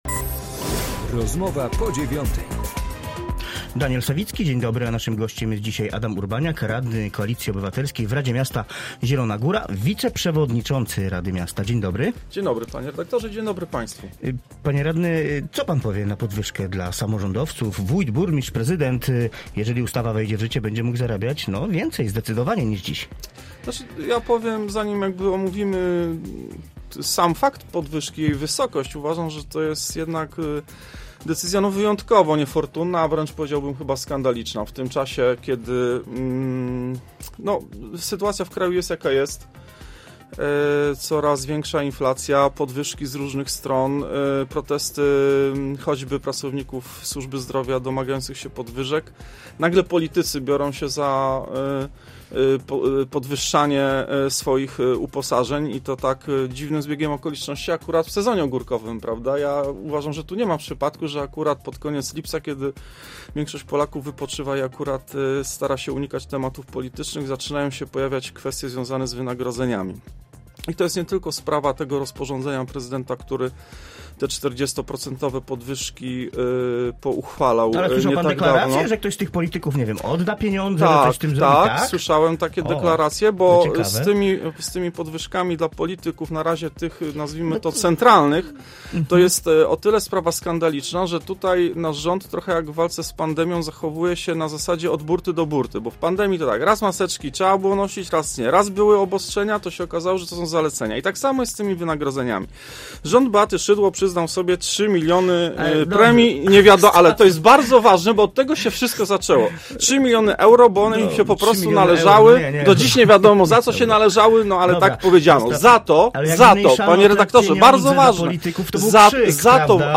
Z wiceprzewodniczącym rady miasta, radnym klubu Koalicji Obywatelskiej rozmawia